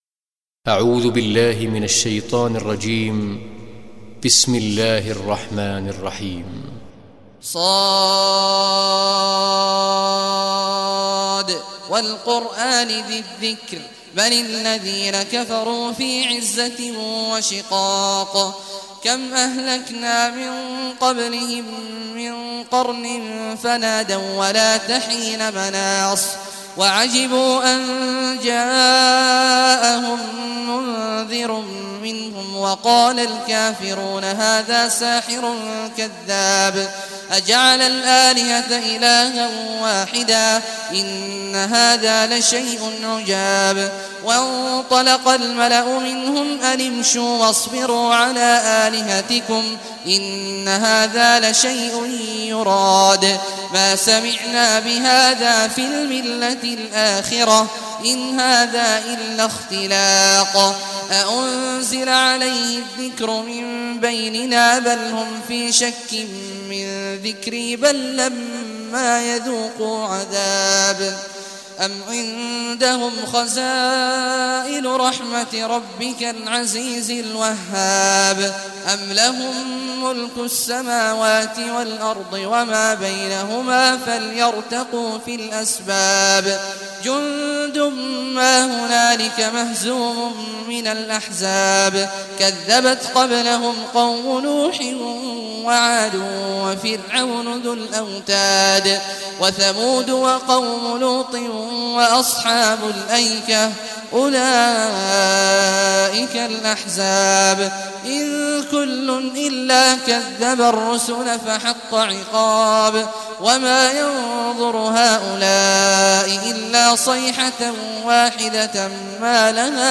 Surah Sad Recitation by Sheikh Abdullah al Juhany
Surah Sad, listen or play online mp3 tilawat / recitation in Arabic in the beautiful voice of Sheikh Abdullah Awad al Juhany.